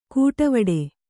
♪ kūṭavaḍe